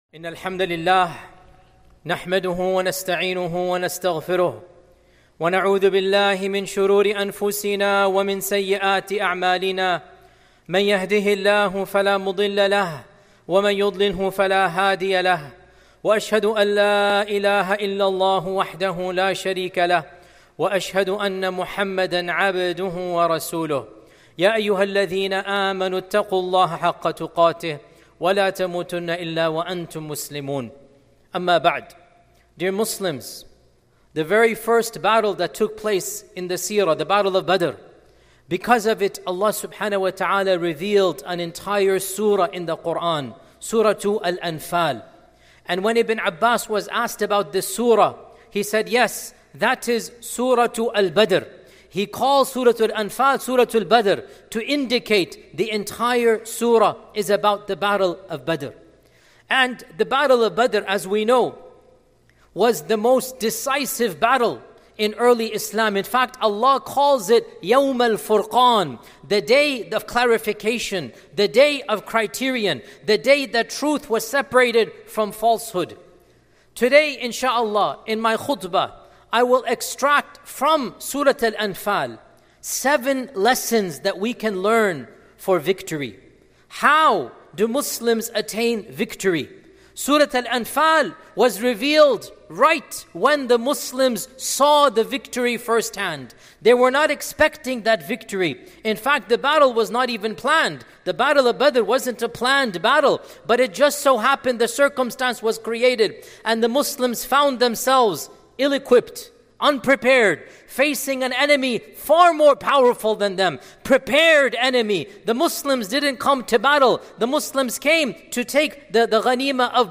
A Simple Formula for Muslim Victory! - Khutbah by Shaykh Dr. Yasir Qadhi.mp3